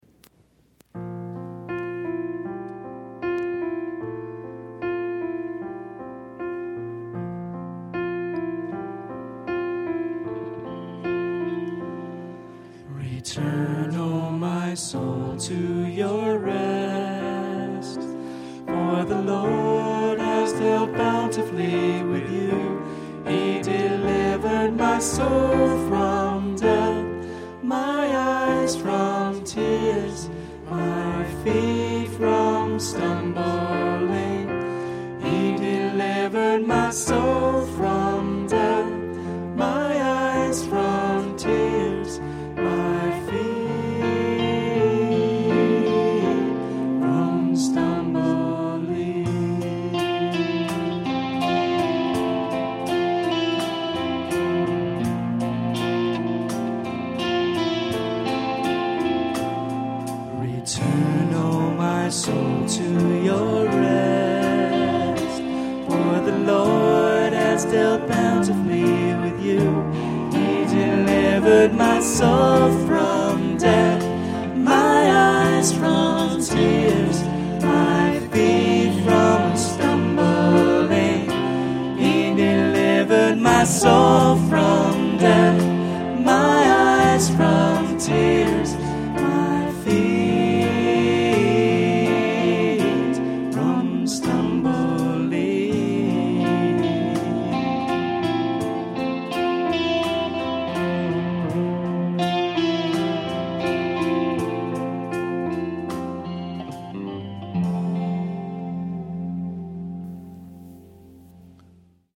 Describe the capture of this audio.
MP3 live at Faith